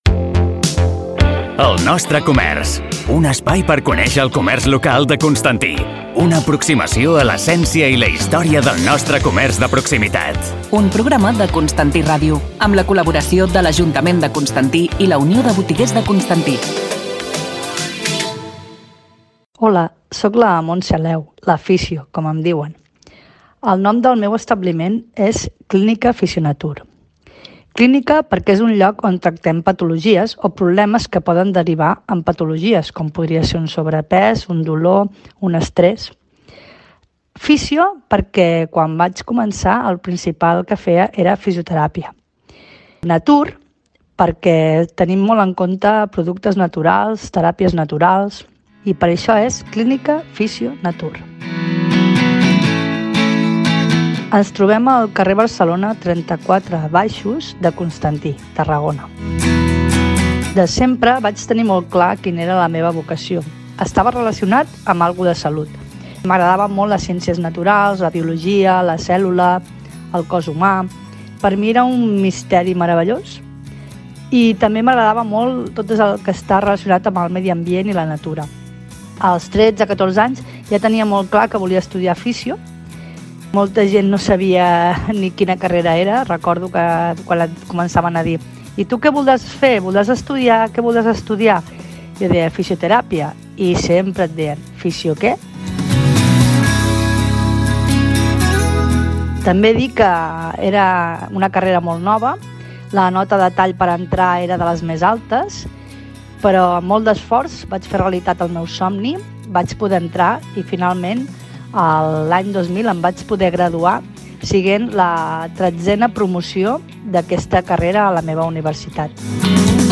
🔊 Coincidint amb la iniciativa que han posat en marxa l’Ajuntament de Constantí i la UBConstanti per donar a conèixer els comerços locals, avui estrenem a Constantí Ràdio la versió radiofònica de “El nostre comerç”, un espai on cada mes els nostres botiguers seran els protagonistes i ens explicaran la història, el present i la raó de ser dels seus establiments. Comencem avui amb el reportatge sobre la Clínica FISIONATUR